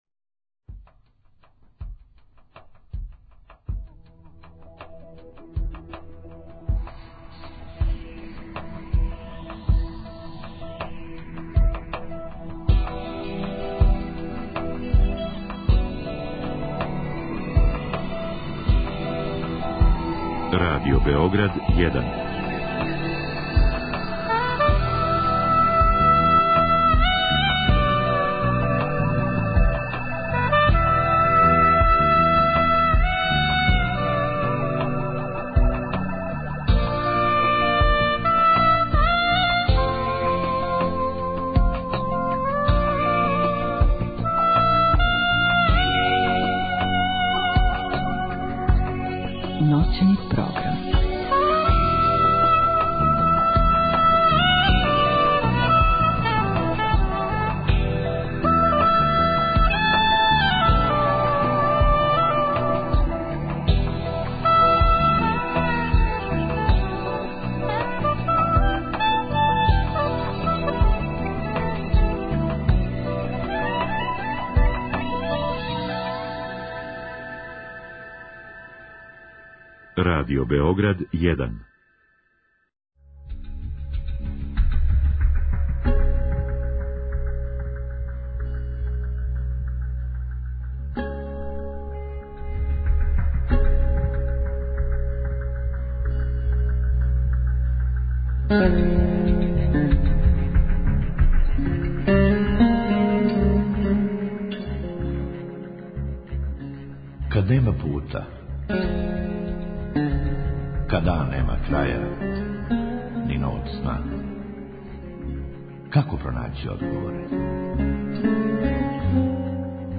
У другом сату емисије слушаоци могу директно да се укључе у програм са својим коментарима, предлозима и проблемима који се односе на психолошки свет, међу свим другим световима.